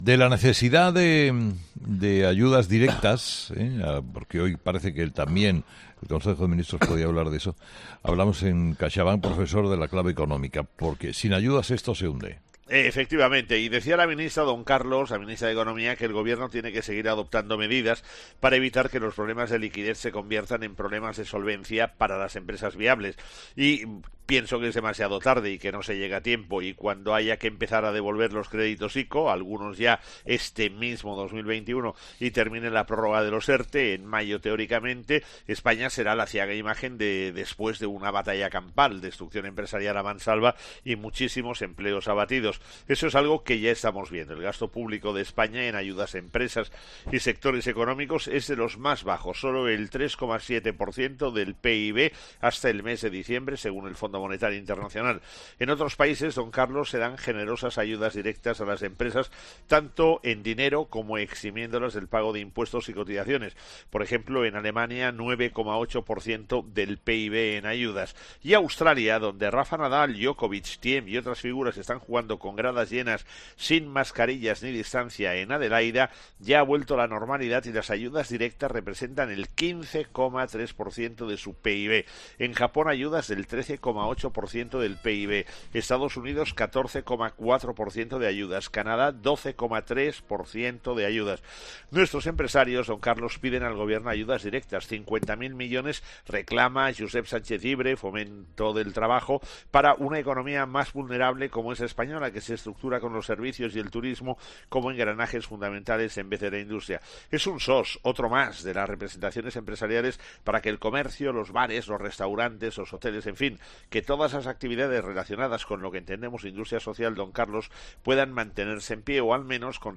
El profesor José María Gay de Liébana analiza en 'Herrera en COPE’ las claves económicas del día